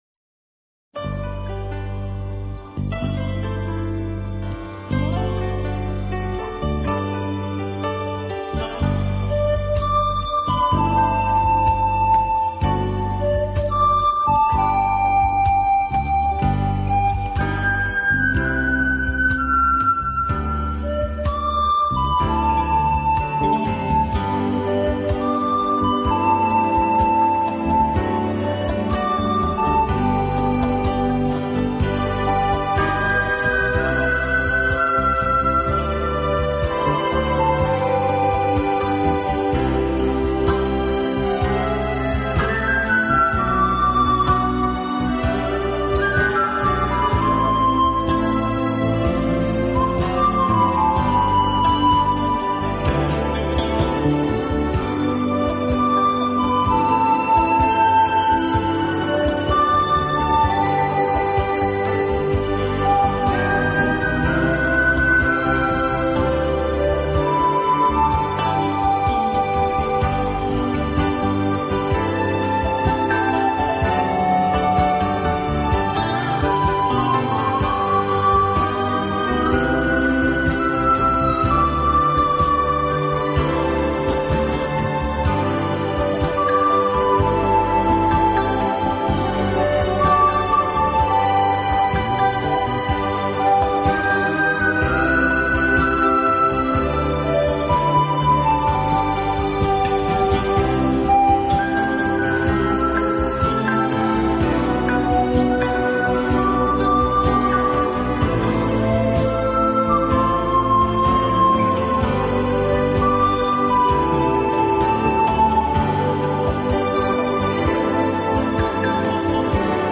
有的音乐改编自古典音乐经典片段、好莱坞浪漫巨片和流行金榜名曲。